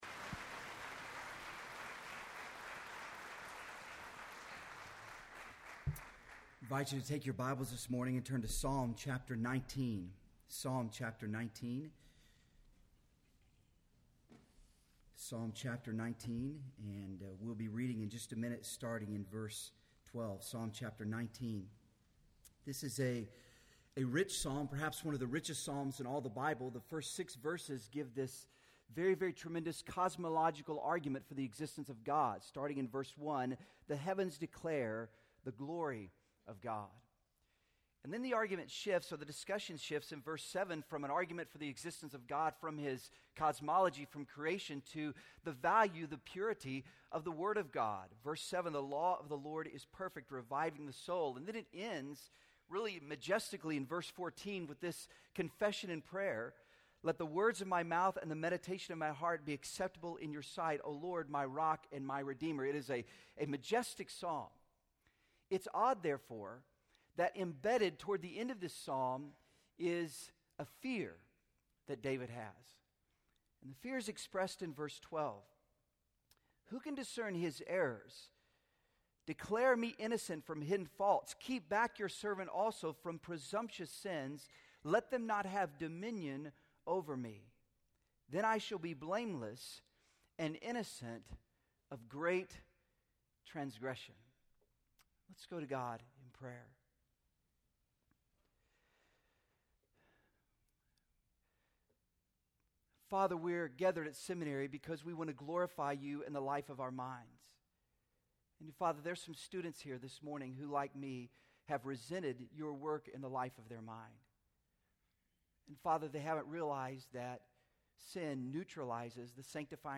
in SWBTS Chapel on Tuesday January 19, 2010